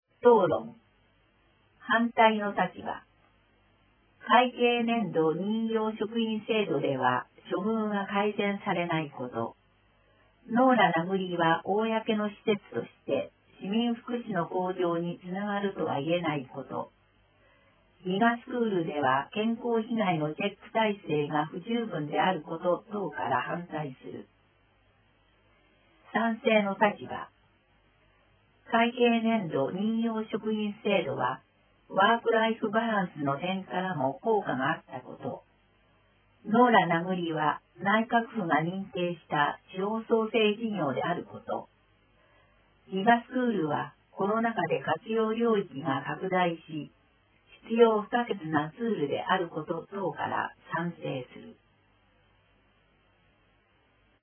声の議会だより第161号(mp3)